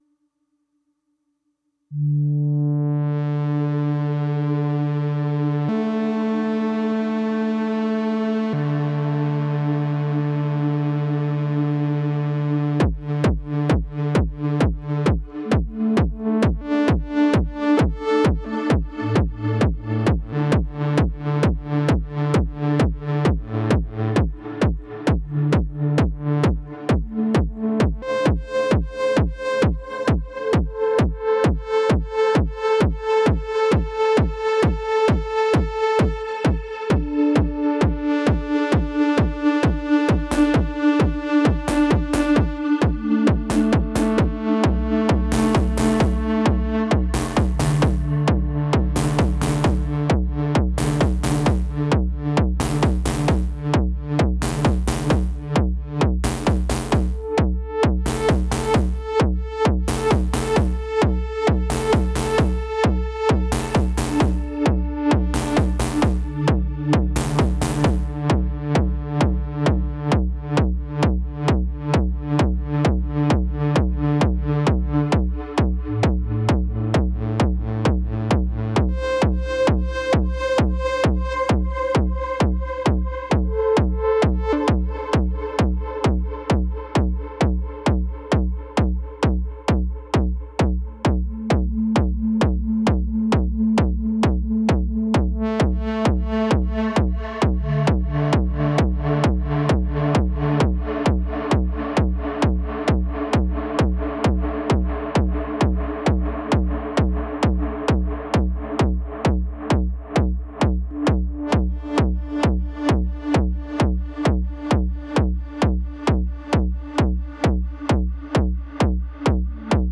Two controls for intensity and shape length allow for adjustment of the curve, making ducking effects easy to implement.
>>SIDECHAINER SOUND EXAMPLE (AMPERE)
Flame_SideChainer.mp3